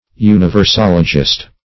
Universologist \U`ni*ver*sol"o*gist\, n. One who is versed in universology.
universologist.mp3